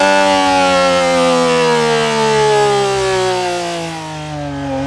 rr3-assets/files/.depot/audio/Vehicles/f1_04/f1_04_decel.wav
f1_04_decel.wav